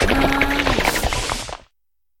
Cri de Vrombi dans Pokémon HOME.